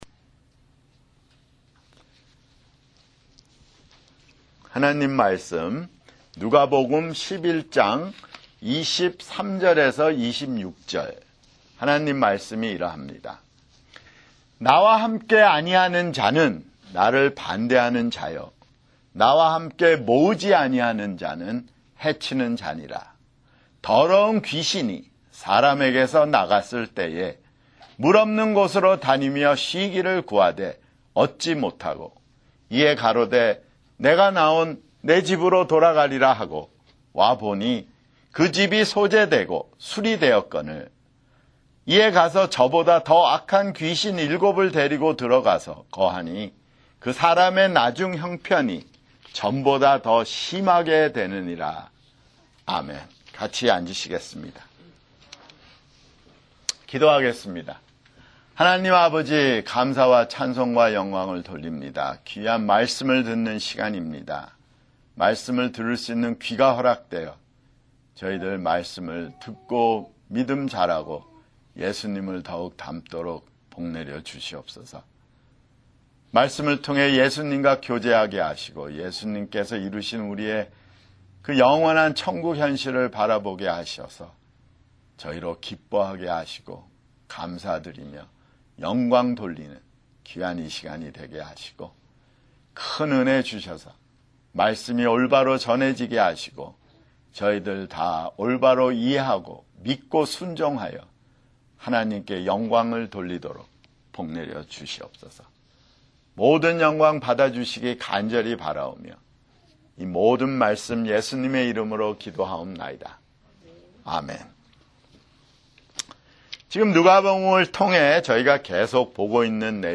[주일설교] 누가복음 (82)